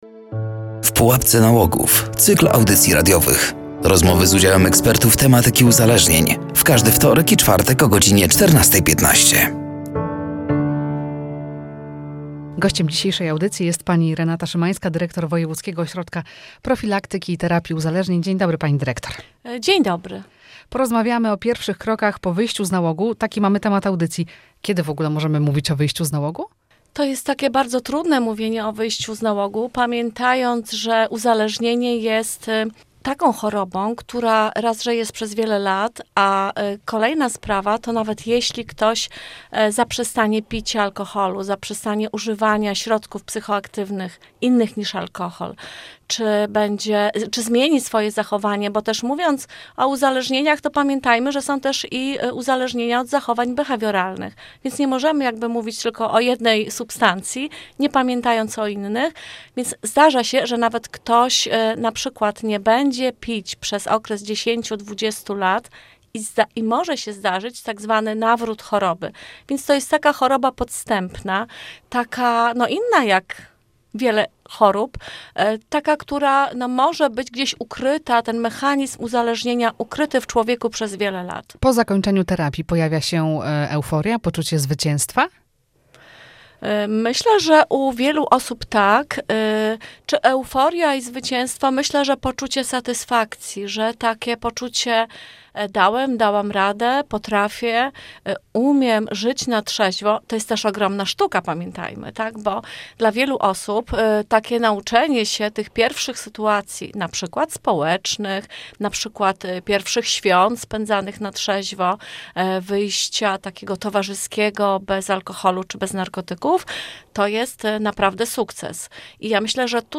“W pułapce nałogów” cykl audycji radiowych poświęconych profilaktyce uzależnień wśród dzieci i młodzieży. Rozmowy z udziałem ekspertów tematyki uzależnień.